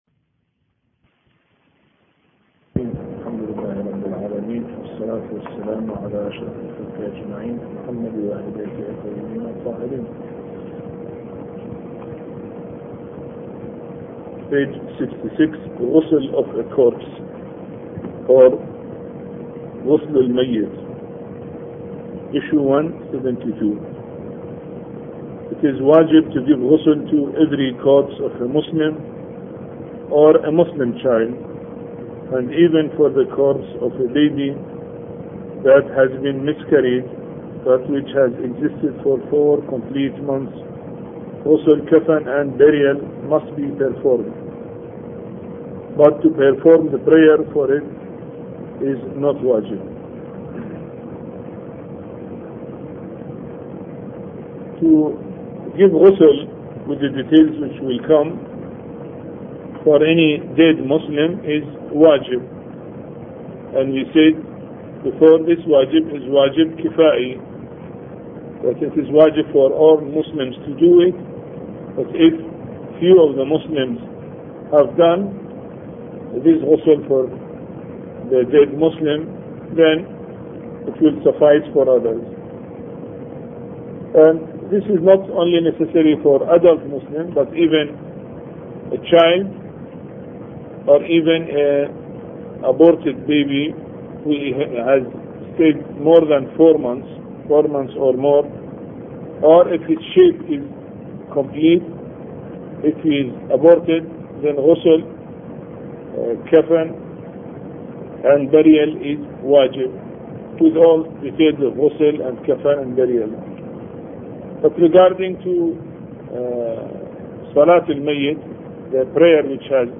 A Course on Fiqh Lecture 9